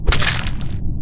Among Us Kill Sound Button: Unblocked Meme Soundboard
Play the iconic Among Us Kill Sound Button for your meme soundboard!